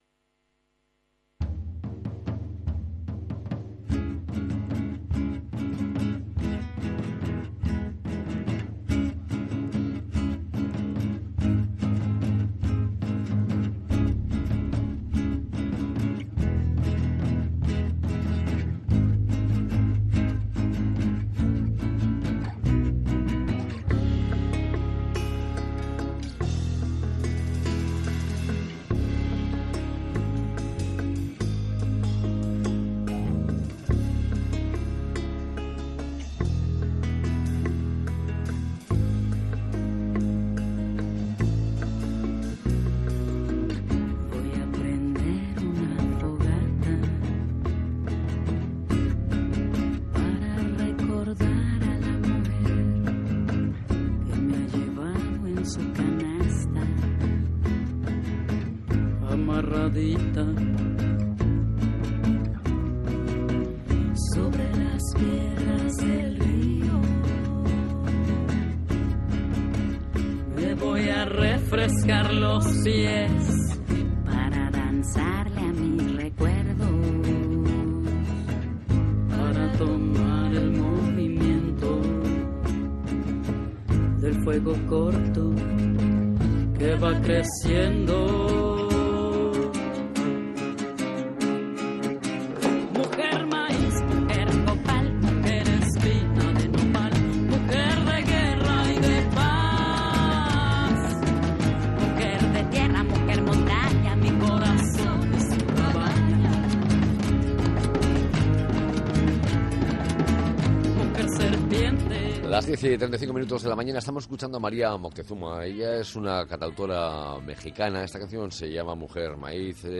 ENTREVISTA-OV-SOBERANIA-ALIMENTARIA-30-04.mp3